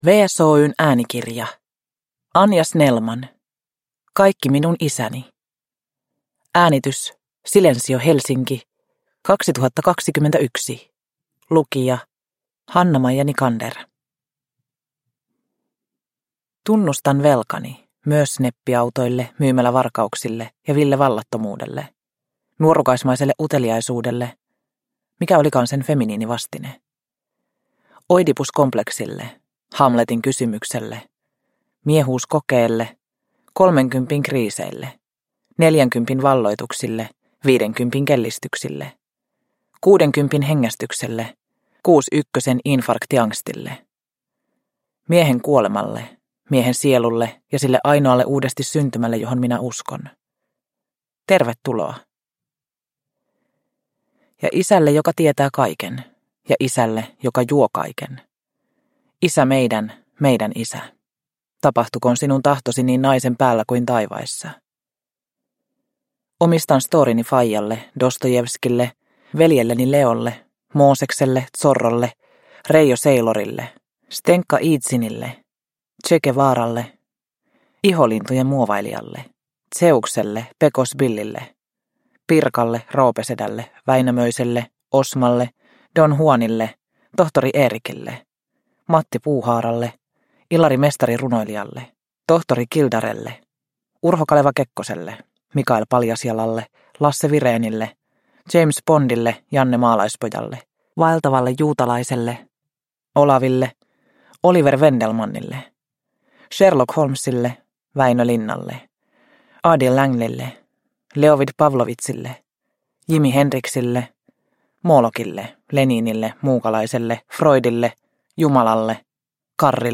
Kaikki minun isäni – Ljudbok – Laddas ner